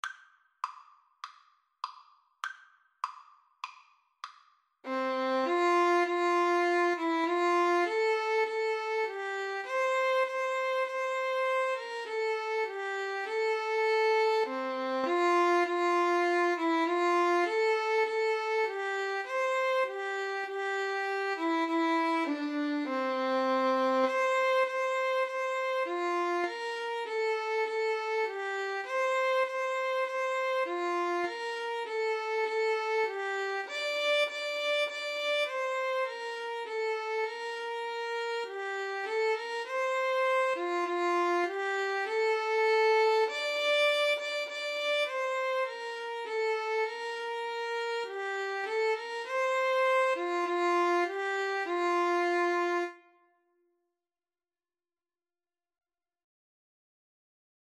4/4 (View more 4/4 Music)
Classical (View more Classical Violin-Cello Duet Music)